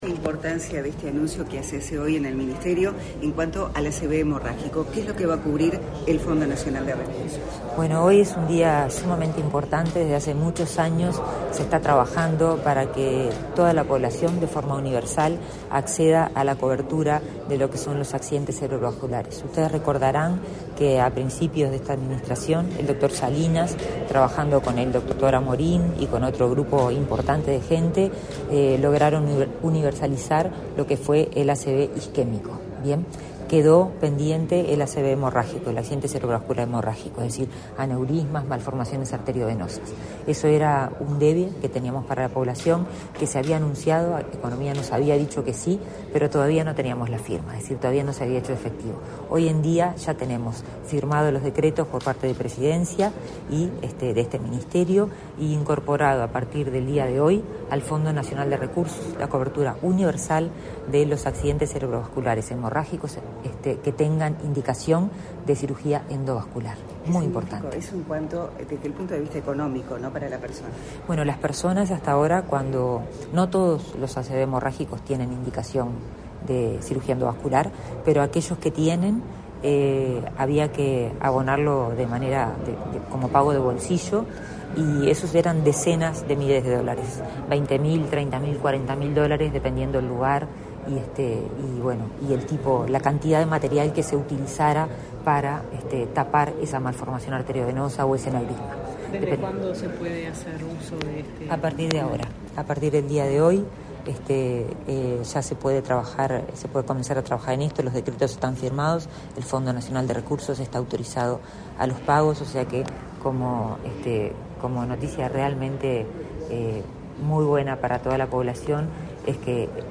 Declaraciones de la ministra de Salud Pública, Karina Rando | Presidencia Uruguay
Declaraciones de la ministra de Salud Pública, Karina Rando 27/01/2025 Compartir Facebook X Copiar enlace WhatsApp LinkedIn Tras anunciar la incorporación del tratamiento para accidentes cerebrovasculares (ACV) hemorrágicos a las prestaciones del Fondo Nacional de Recursos, este 27 de enero, la ministra de Salud Pública, Karina Rando, realizó declaraciones a la prensa.